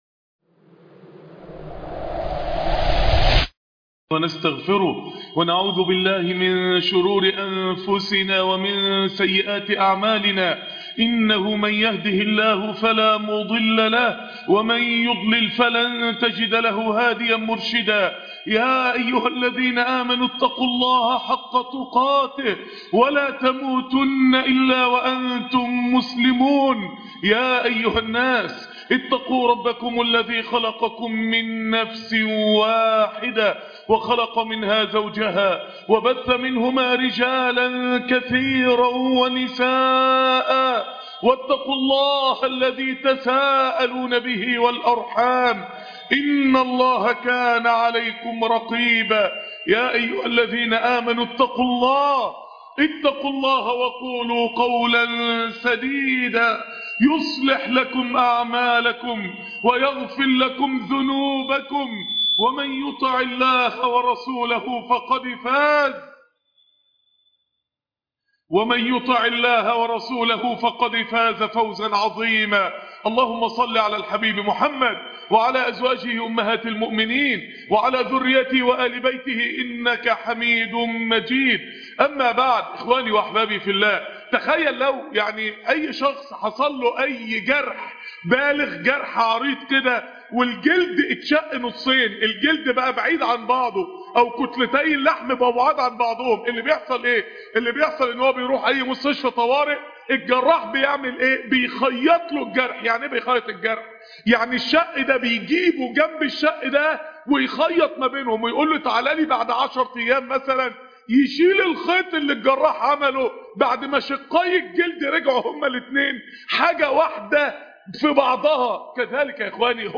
قلوب صنعها القرآن " نور على نور " ( خطب الجمعة )